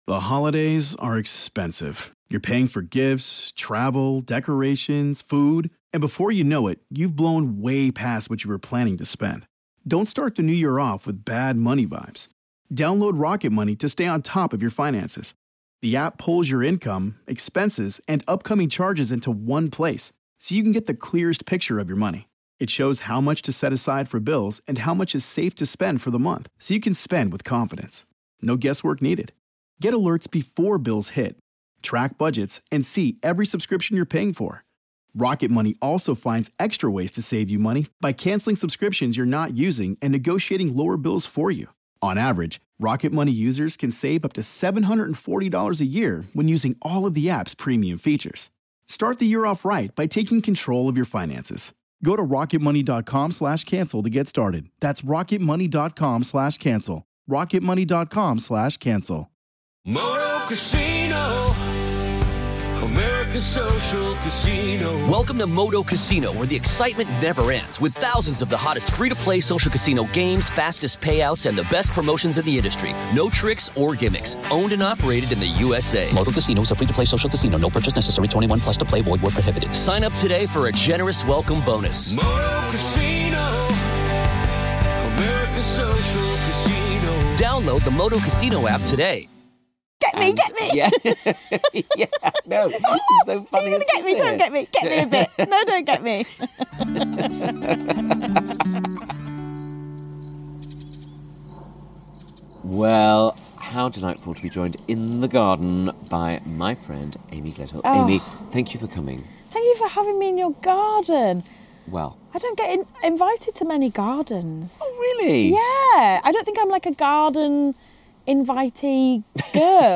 Joining me this week among the perennials is the fabulous Amy Gledhill!
Yes, she is very busy, which makes me even more grateful she popped by for a chat.